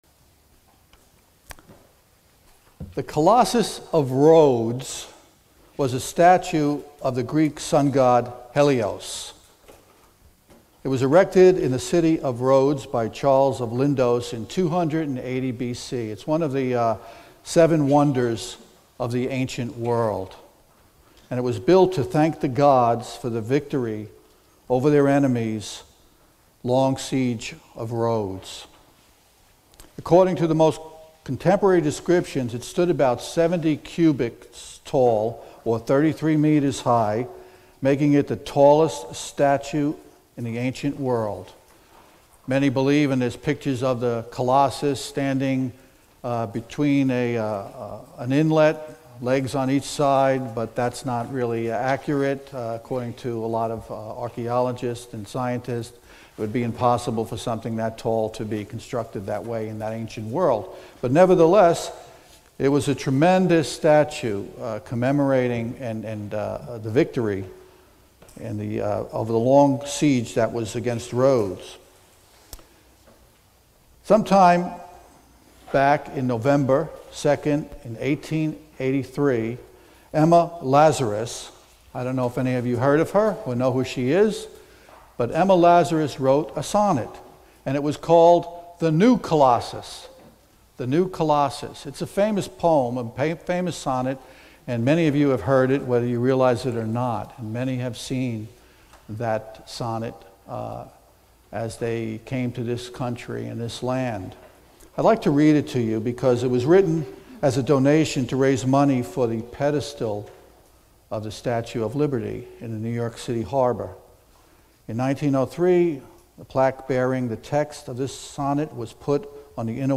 Sermons
Given in North Canton, OH Sugarcreek, OH